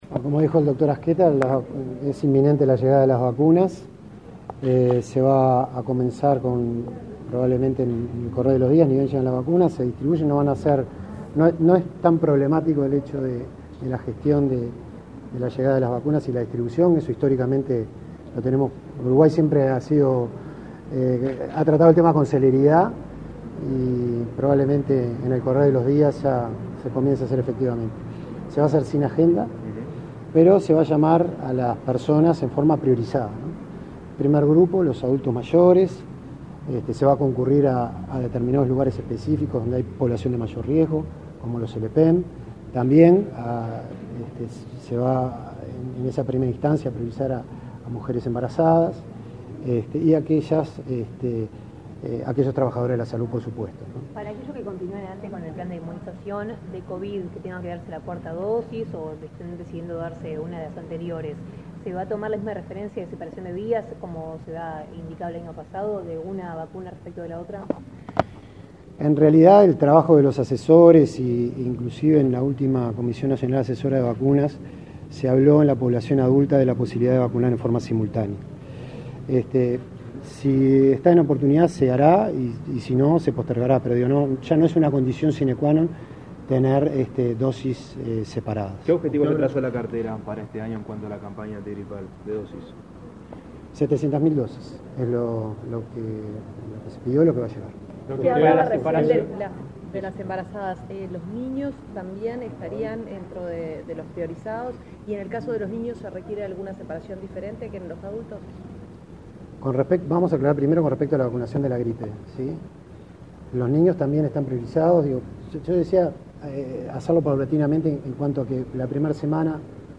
Declaraciones a la prensa del director de Inmunizaciones del MSP, Gabriel Peluffo
Este 25 de abirl se lanzó la 20.ª Semana de Vacunaciones de las Américas, con la participación de autoridades del Ministerio de Salud Pública (MSP) y de referentes de la División de Epidemiología y de la Unidad de Inmunizaciones de esa dependencia estatal. Tras el evento, Daniel Peluffo efectuó declaraciones a la prensa.